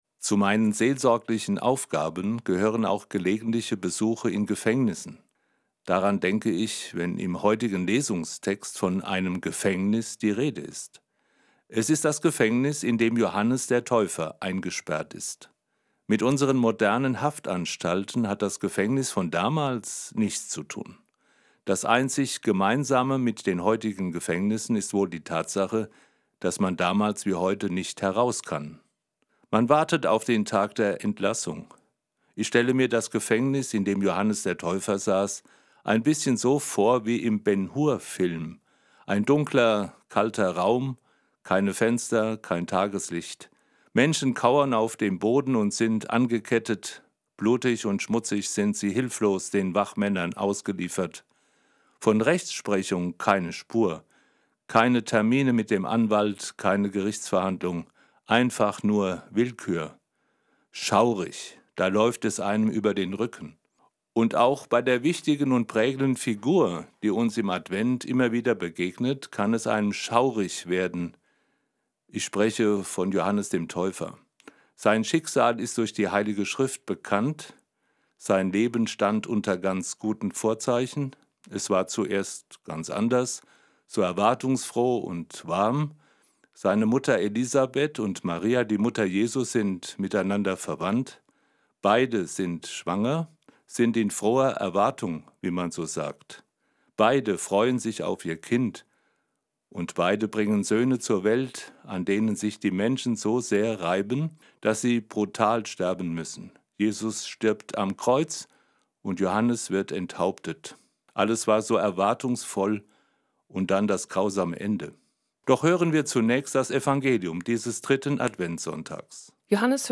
Der Advent ist die Einladung, das von Jesus geschenkte Heil persönlich zu ergreifen und dieser Weihnachtszeit eine tiefe, tragende Beziehung zu geben. Darüber spricht Weihbischof Karlheinz Diez aus Fulda.
Eine Sendung von Prof. Dr. Karlheinz Diez, Katholischer Weihbischof, Fulda